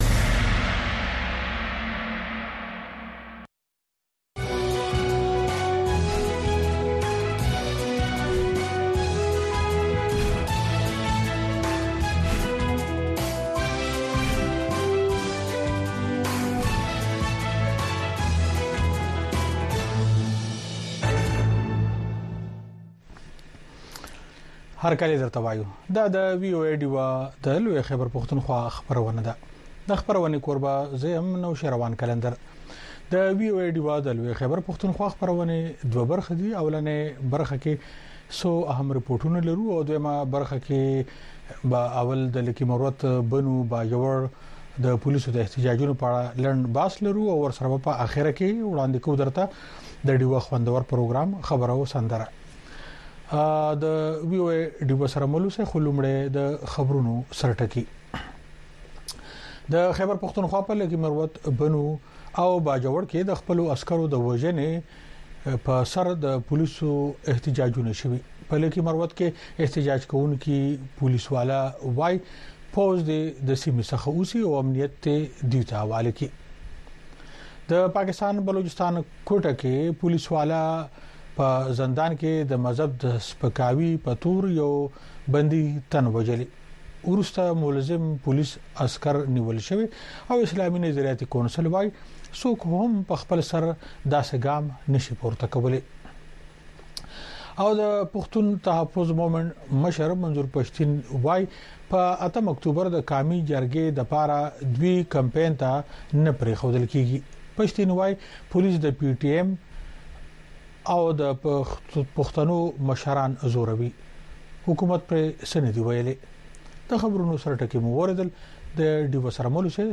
خبرونه
د وی او اې ډيوه راډيو خبرونه چالان کړئ اؤ د ورځې د مهمو تازه خبرونو سرليکونه واورئ.